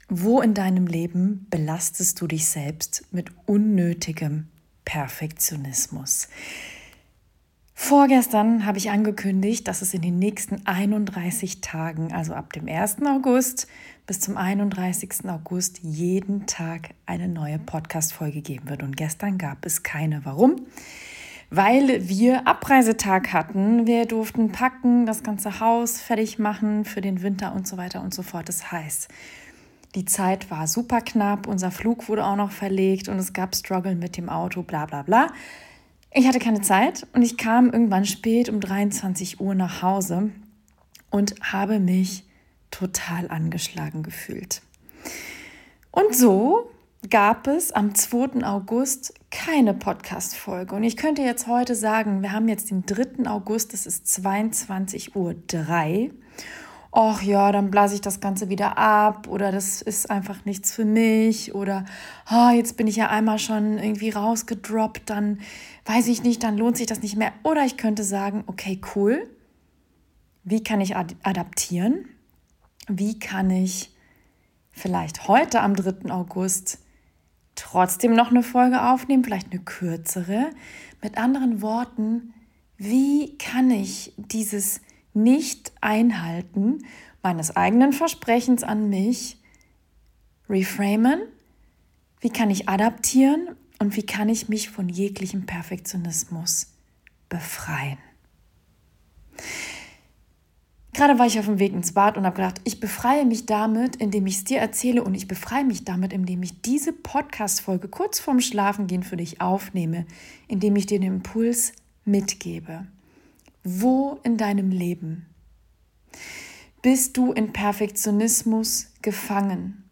Real, raw und ungeschnitten.